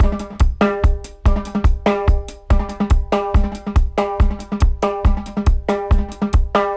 142-DRY-05.wav